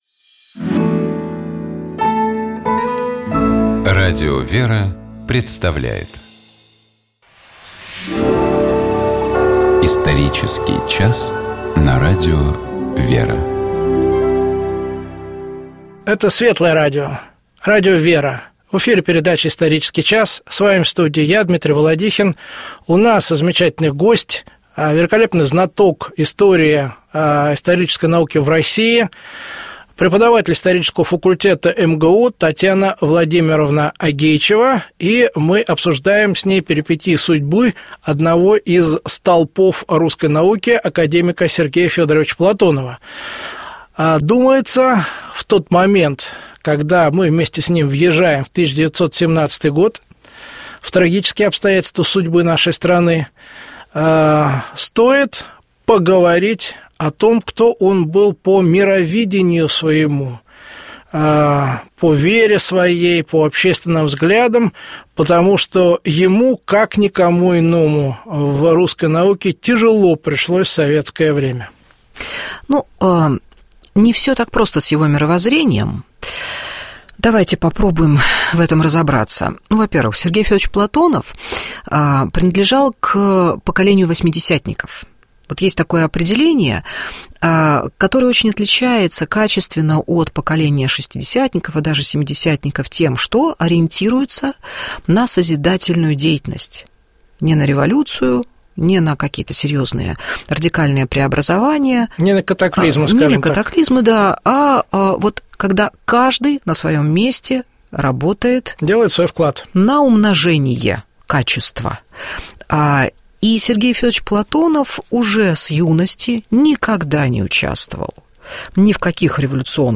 Это светлое радио, радио «Вера».